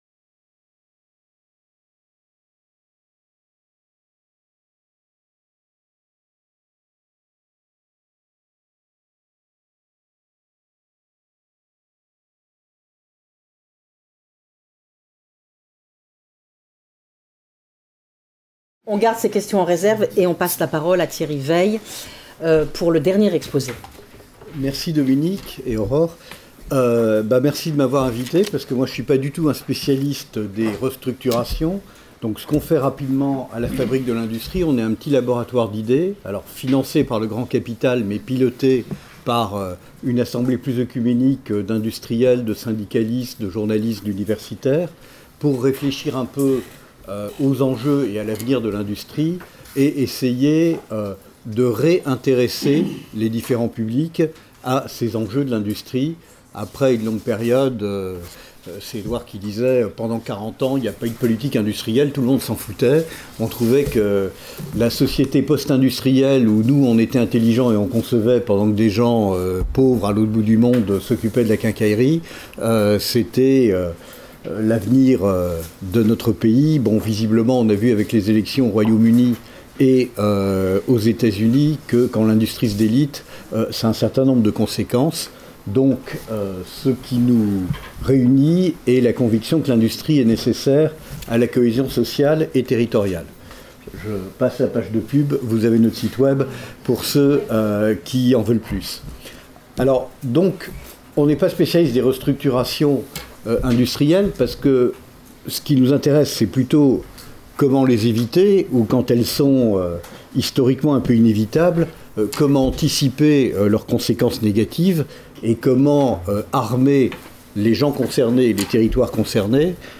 Séminaire animé par Dominique Méda et Aurore Lalucq